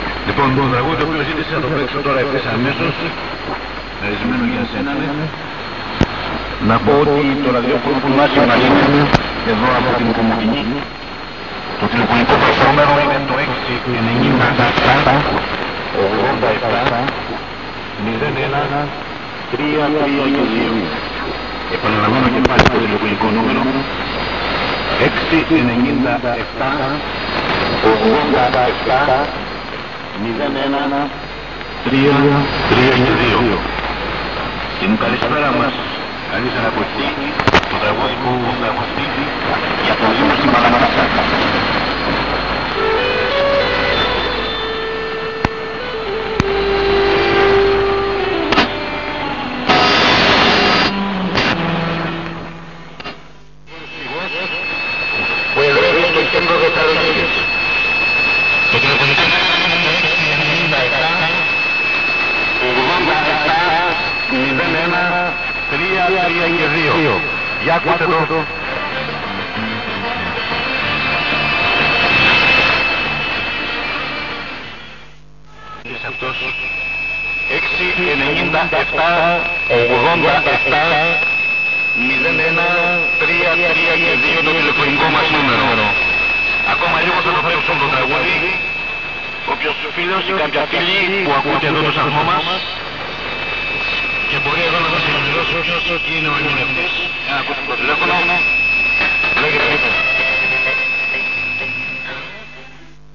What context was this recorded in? Pirate radio from Greece received in Finland I have 300 meters longwire directed to South and it gives good signals.